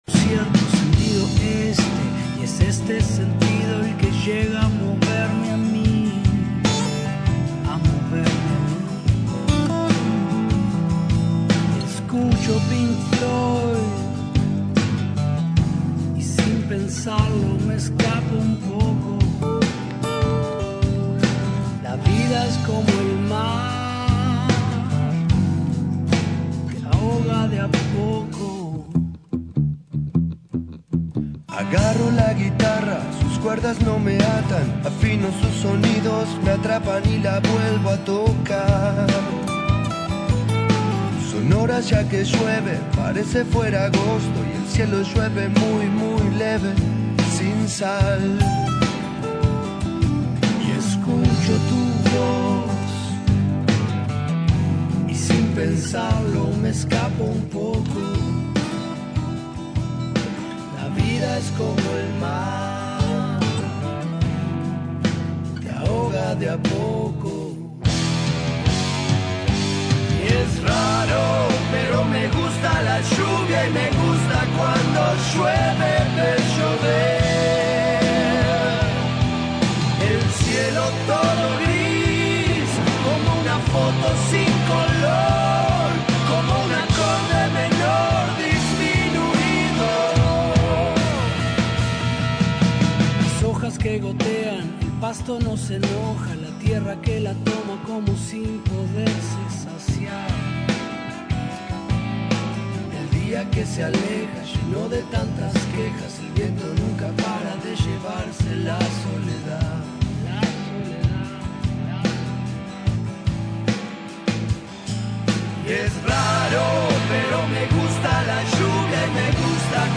La Triple Nelson visitó los estudios de Rompkbzas.
Y de yapa se tocaron una.